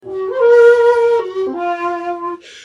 SONS ET LOOPS DE SHAKUHACHIS GRATUITS
Shakuhachi 41